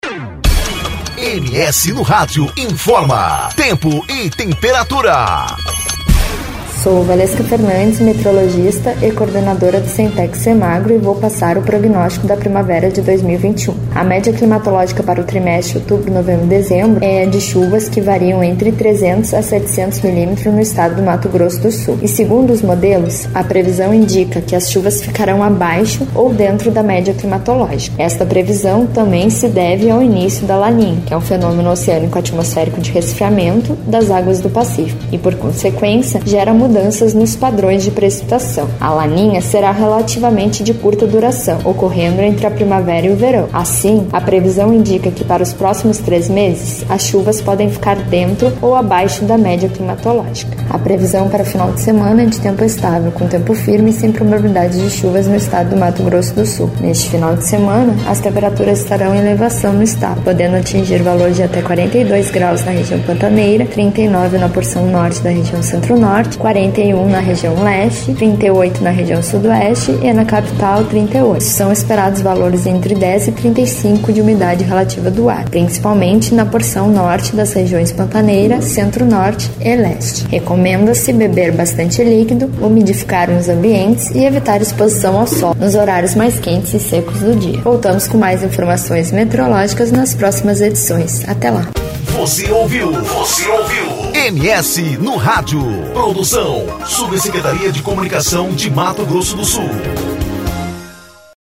Previsão do Tempo: Final de semana de tempo estável e sem chuvas